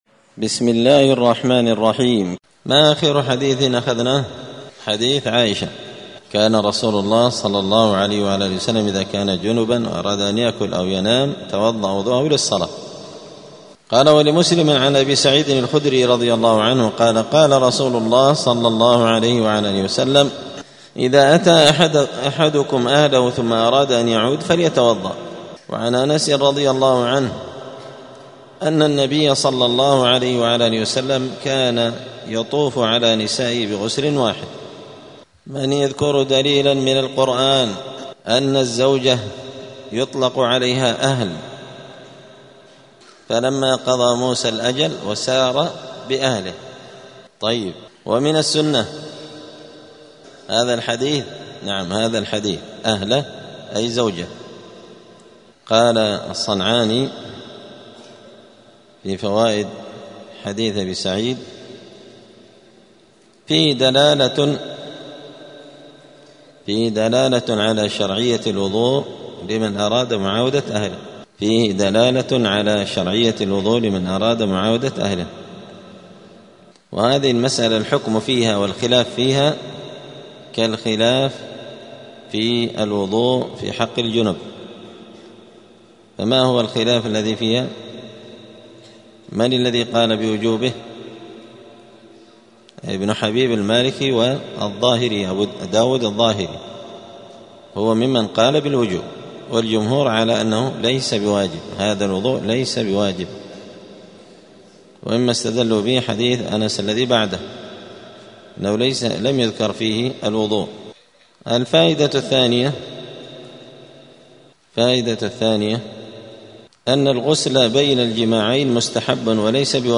دار الحديث السلفية بمسجد الفرقان قشن المهرة اليمن
*الدرس السادس والسبعون [76] {باب مايوجب الغسل، طهارة بدن وثوب وعرق الجنب}*